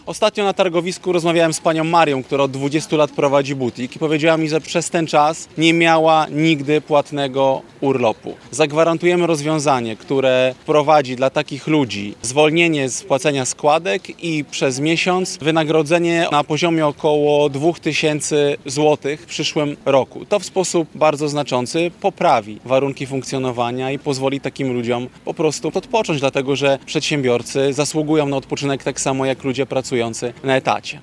Politycy Koalicji Obywatelskiej podczas konferencji prasowej mówili o kolejnych propozycjach programowych. Konkrety, które chcą wdrożyć w życie po tym, gdy wygrają wybory, przedstawione zostały na szczecińskim targowisku Turzyn.